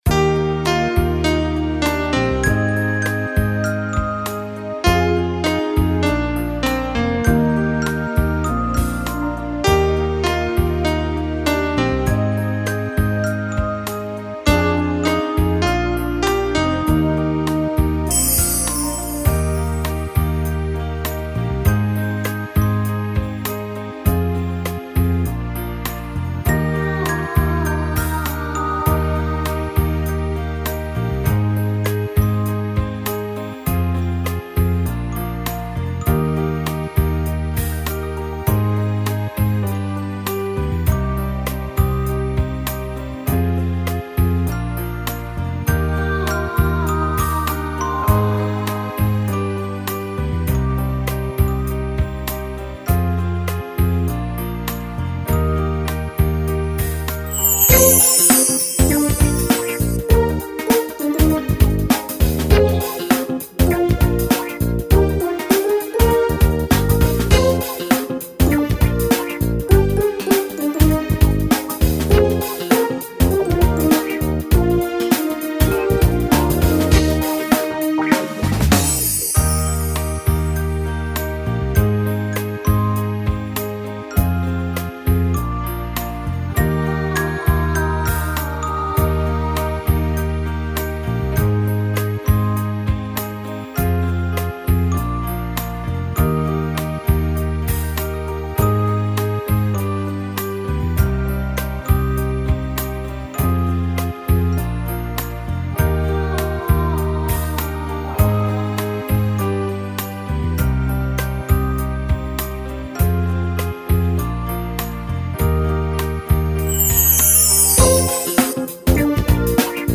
Минусовки: